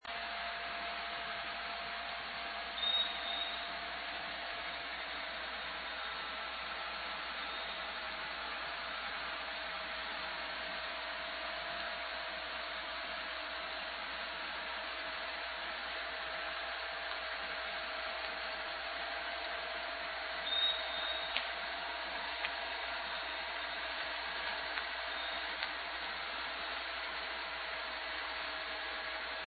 2 pings of a 3.75 kHz sonar